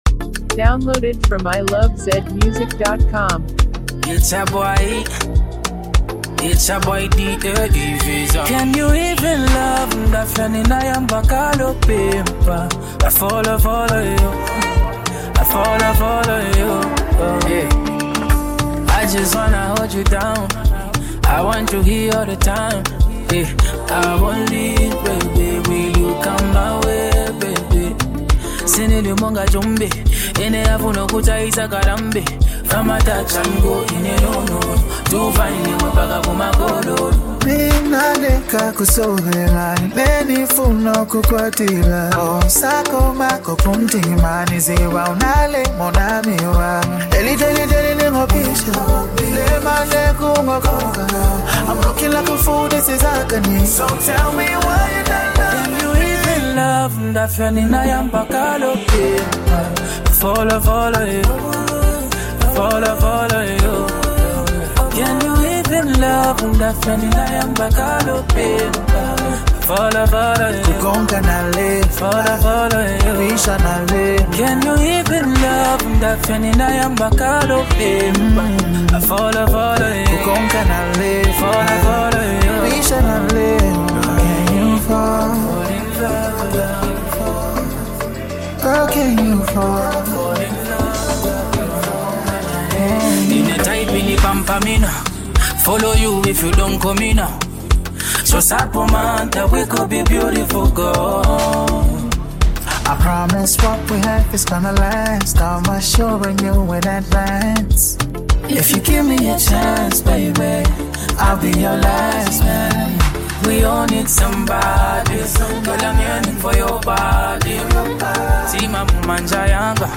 talented R&B singer and producer